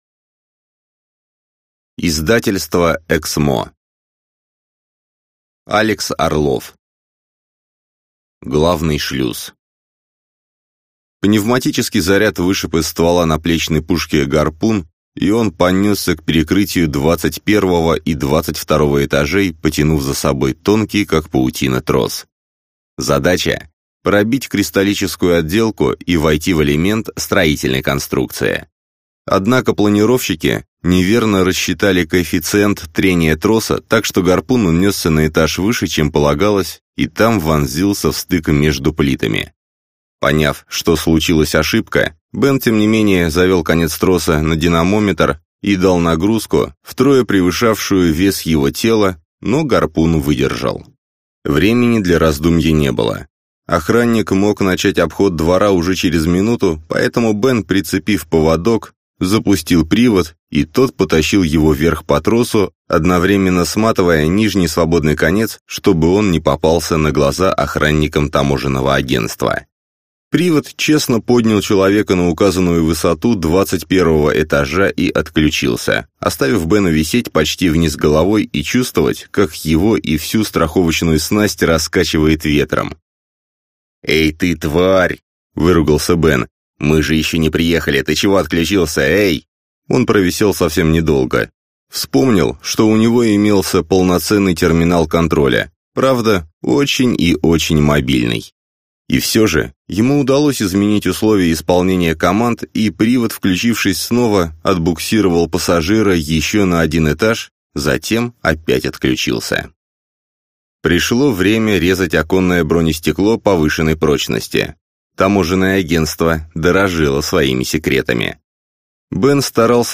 Аудиокнига Главный шлюз | Библиотека аудиокниг